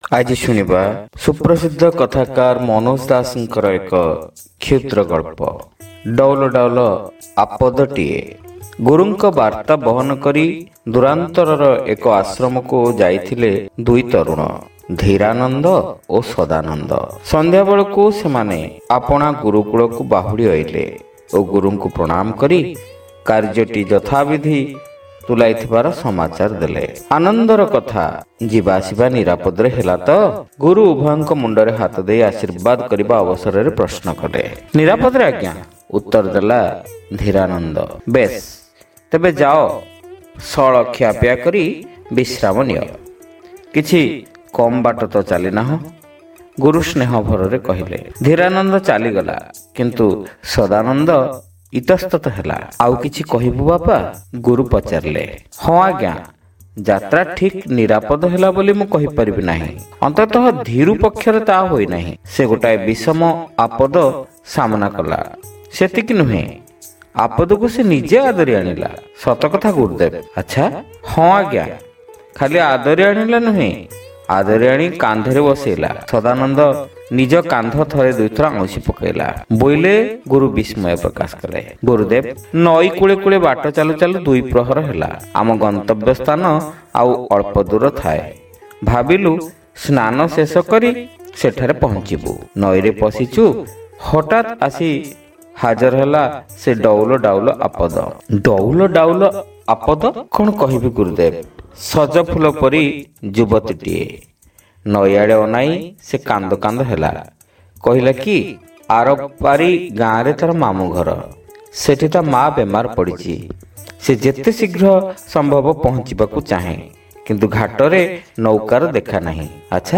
ଶ୍ରାବ୍ୟ ଗଳ୍ପ : ଡଉଲ ଡାଉଲ ଆପାଦଟିଏ